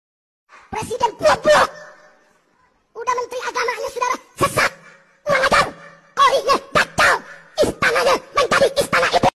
President Goblok Pitched Up Sound Effect Download: Instant Soundboard Button